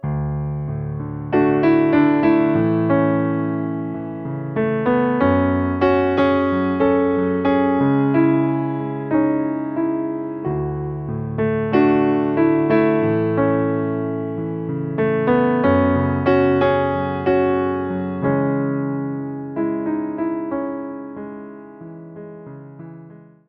który urzeka nastrojowym brzmieniem i delikatnością.
Wersja demonstracyjna:
46 BPM
A – dur